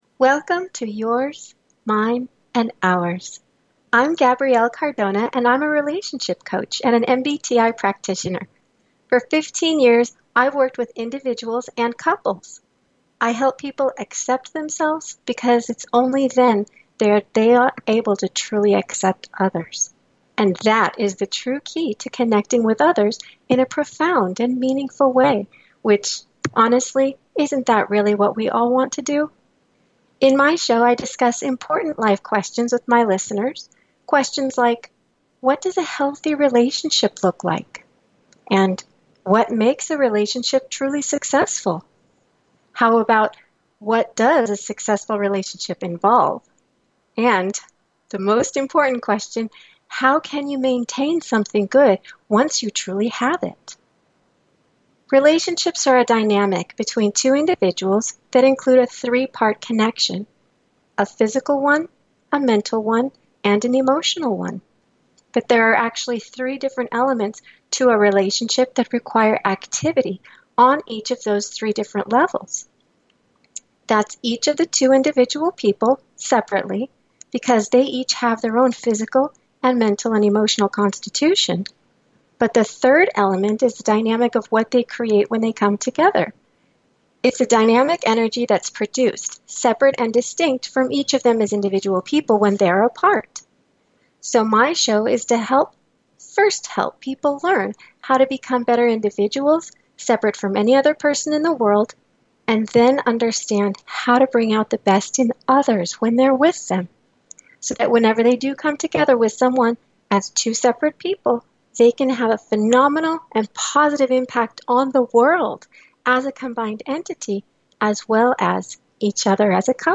Talk Show Episode, Audio Podcast, Yours Mine and Ours and The differences between the genders on , show guests , about The differences between the genders, categorized as Health & Lifestyle,Kids & Family,Relationship Counseling,Philosophy,Psychology,Personal Development,Self Help